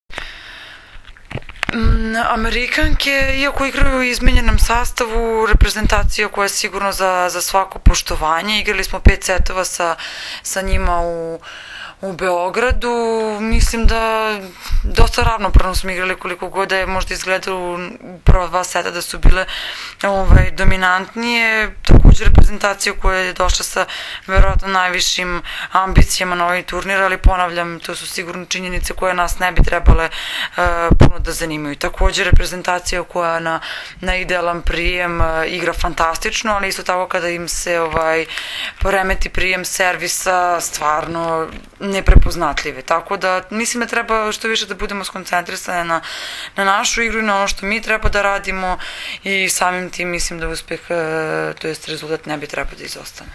IZJAVA MAJE OGNJENOVIĆ 2